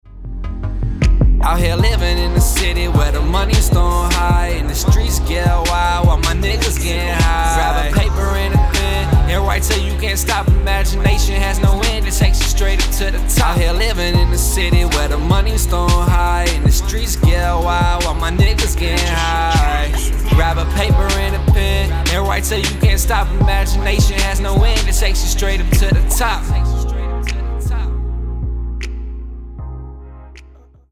View attachment Living in the city - MASTERED_01.mp3 The attached track is something that I have worked on recently. Btw, it is me singing, so any feedback is greatly appreciated!